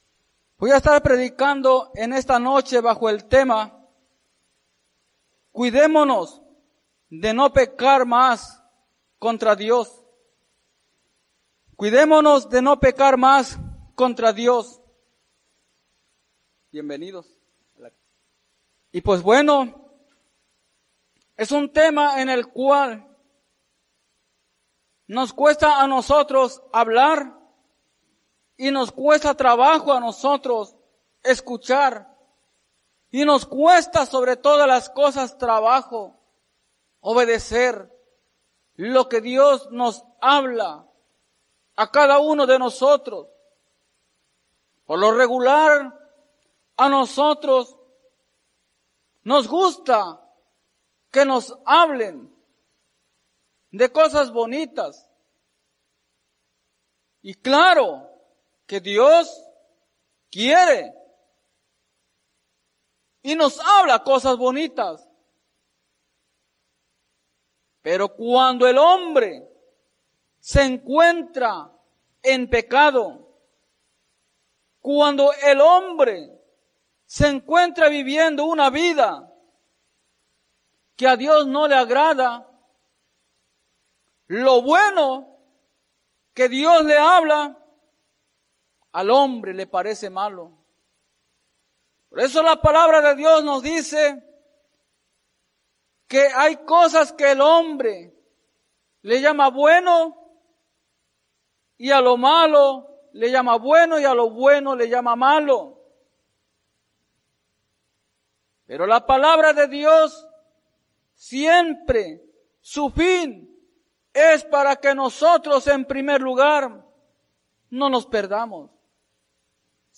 Iglesia Misión Evangélica | Tema: cuidemonos de no pecar más contra Dios. | Predica